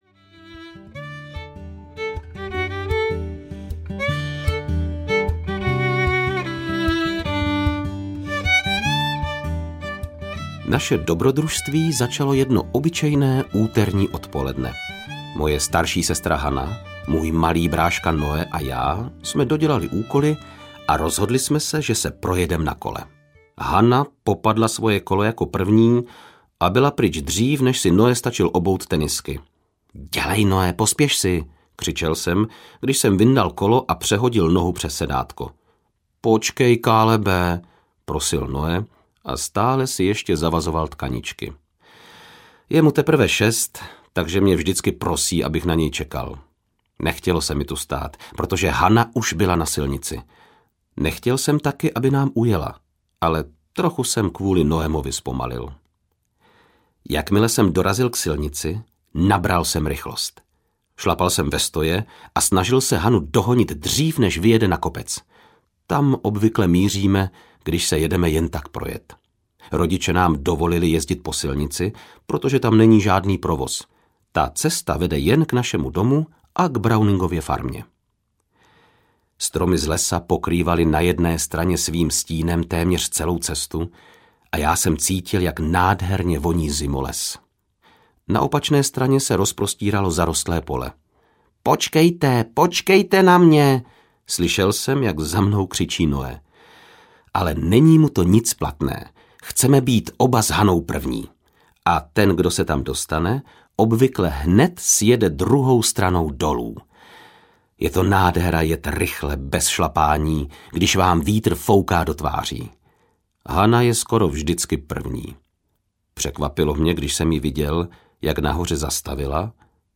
Setkání s pastýři audiokniha
Ukázka z knihy